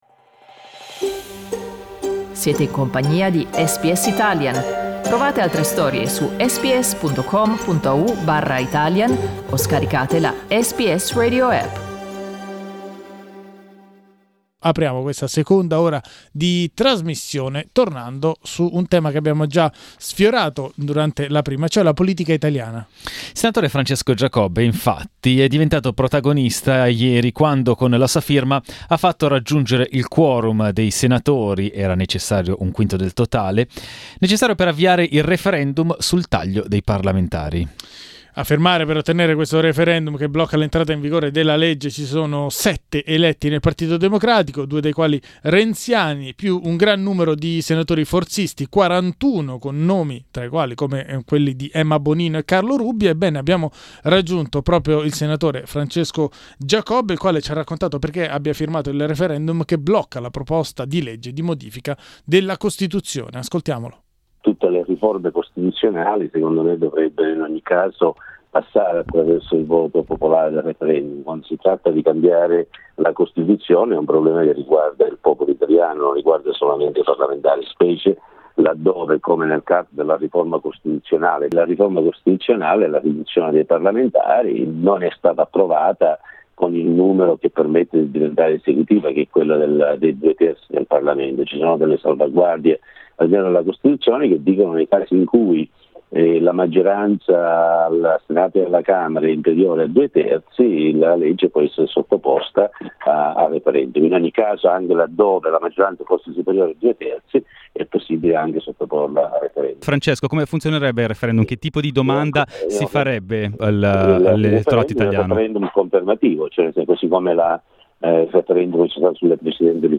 Senator Francesco Giacobbe explains to SBS Italian why he is against the law that cuts the number of parliamentarians in Italy.